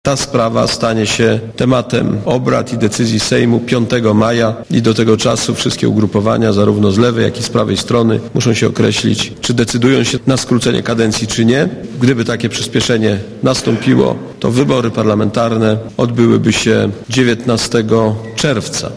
Mówi Aleksander Kwaśniewski
Planowana na godz. 21.30 konferencja prasowa odbyła się z 45 minutowym opóźnieniem i ograniczyła się do wypowiedzi Aleksandra Kwaśniewskiego, który był wyraźnie przeziębiony.